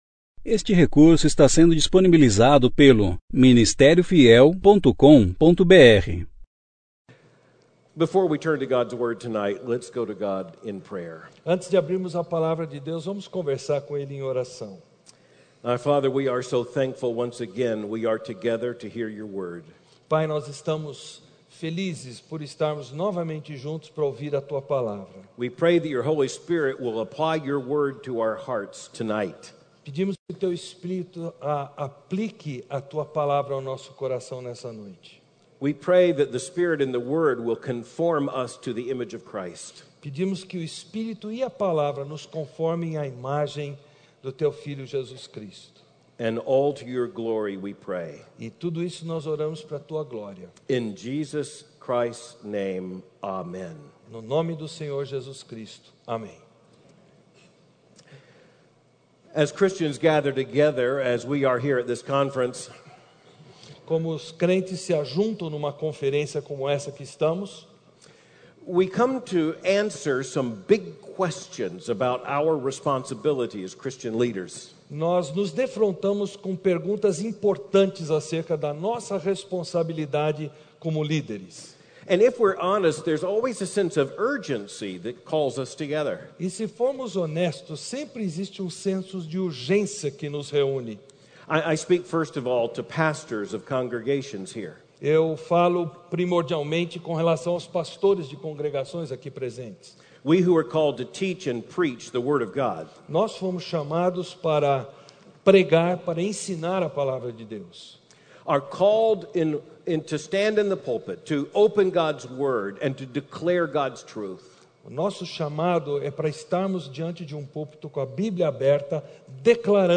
Conferência: 34ª Conferência Fiel para Pastores e Líderes – Brasil Tema: Transformados Ano: 2018 Mensagem: Infiltrações: doutrina falsa e moralidade falsa Preletor: Albert Mohler